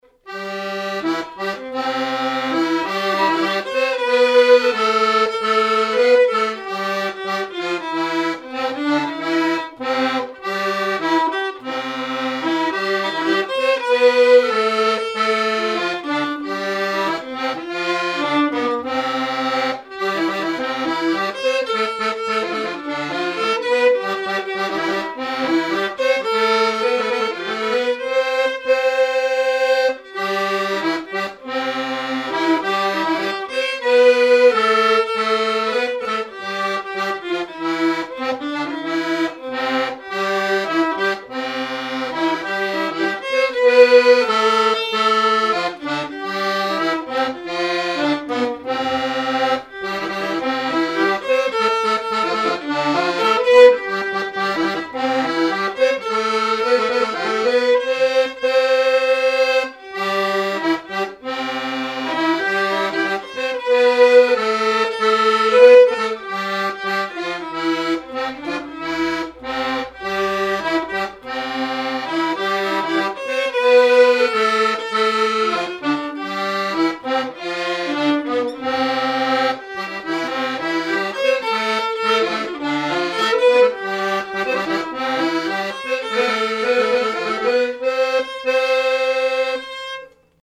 Mémoires et Patrimoines vivants - RaddO est une base de données d'archives iconographiques et sonores.
Répertoire de marches de noces et d'avant-deux
Pièce musicale inédite